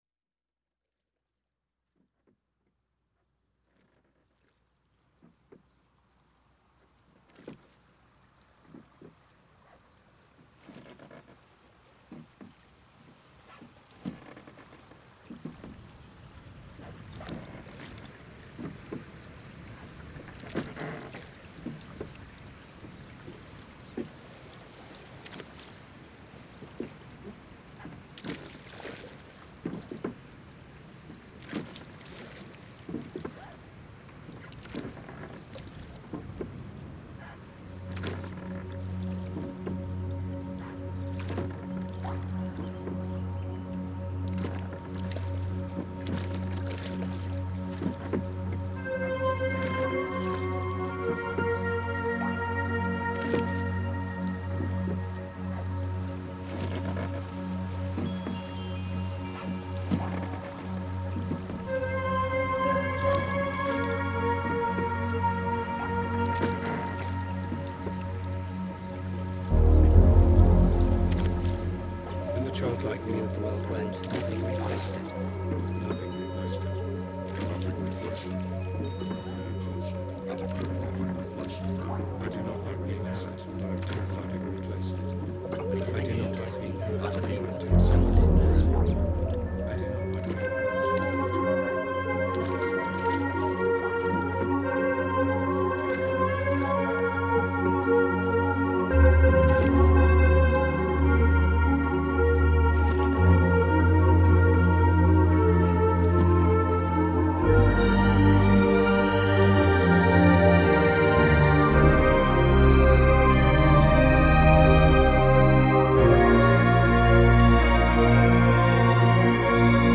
Progressive Rock, Art Rock